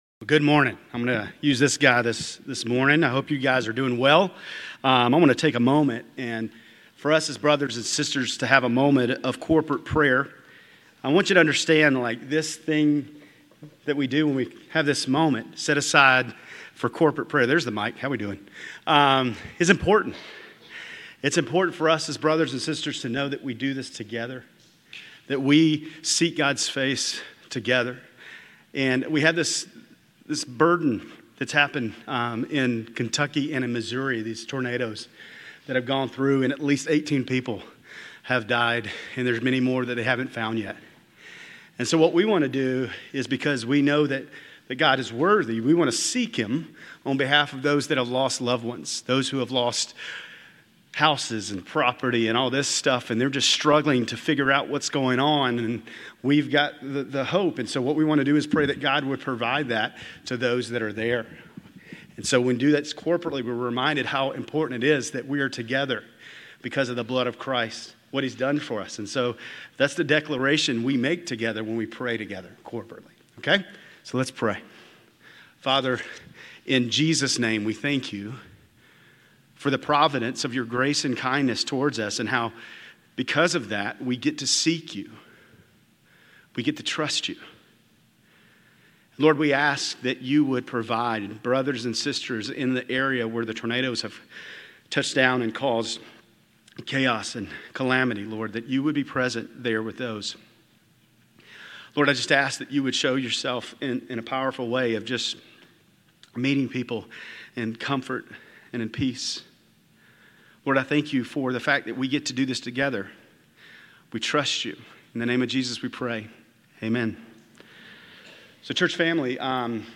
Grace Community Church Lindale Campus Sermons 5_18 Lindale Campus May 19 2025 | 00:32:58 1x 00:00 / 00:32:58 Subscribe Share RSS Feed Share Link Embed